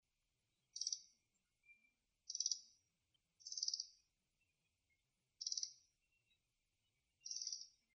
560nishiojirobitaki_jinaki.mp3